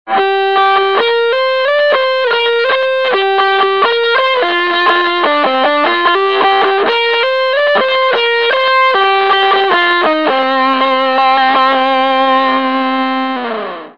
The below clips were recorded with the Small Bear matched set of germanium transistors for the TBMK2.
The first bit (some single notes, then chords) is with Fuzz set at 50%, the second bit has Fuzz set at 100%.
Clip 2 (amp) (276k)
How the clips were recorded:  Pretty guitar - effect - LM386 amp - 2x12 open cab